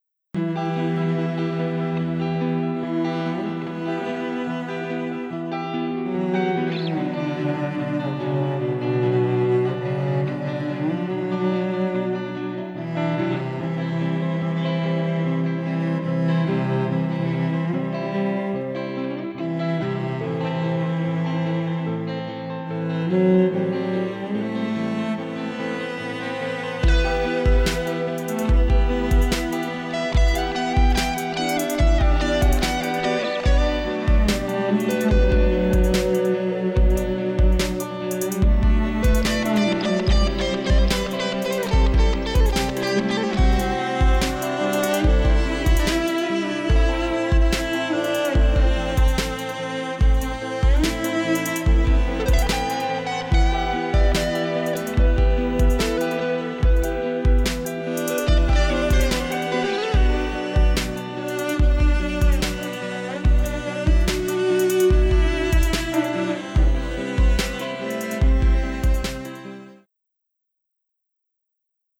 53M 试听： 集电影风格的大提琴和电吉他循环与黑暗，沉思的氛围。